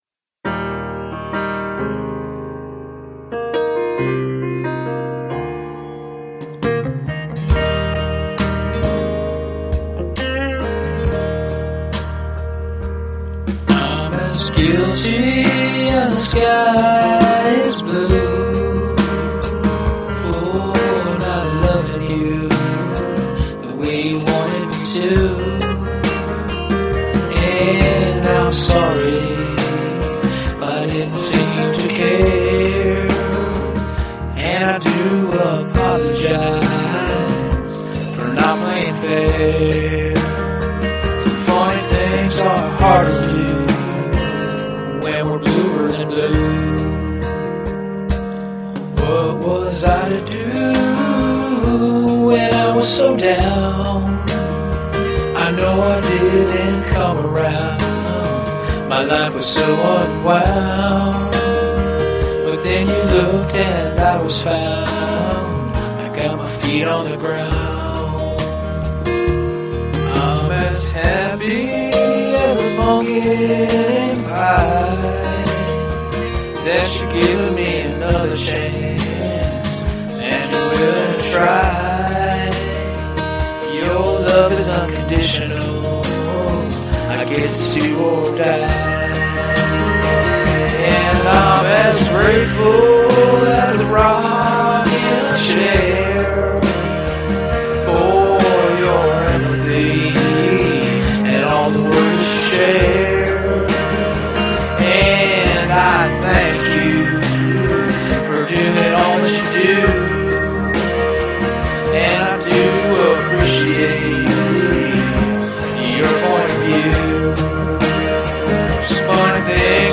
• 5 hours in the studio
I Love the words and feel before and after the chorus part.